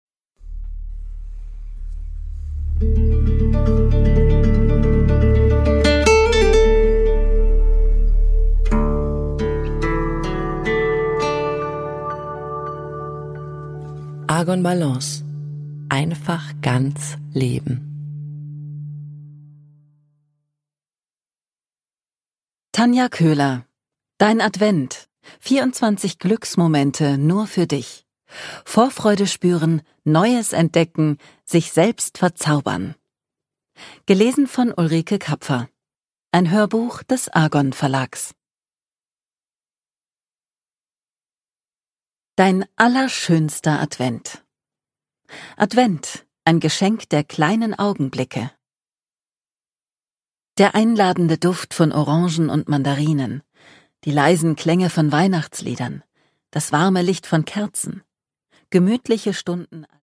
MP3 Hörbuch-Download